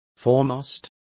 Complete with pronunciation of the translation of foremast.